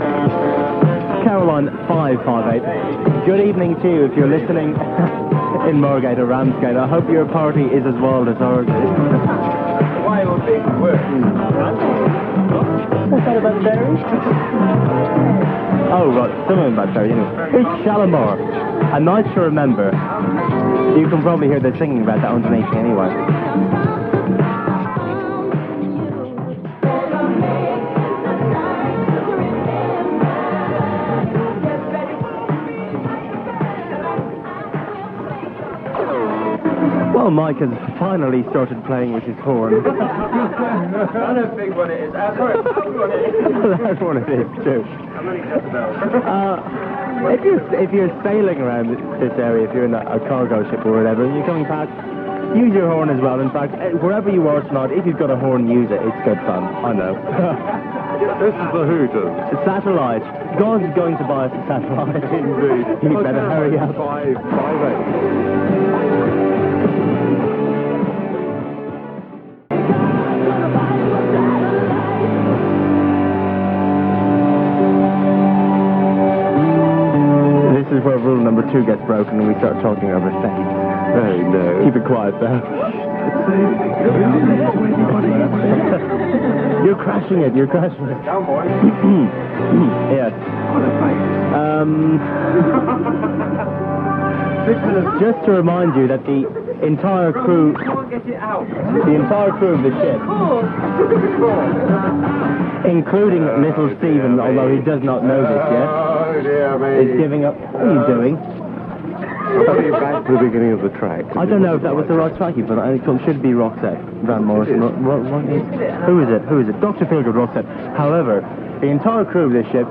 with a studio full of partying colleagues, playing the station's Top 50 singles of the year as voted by the DJs.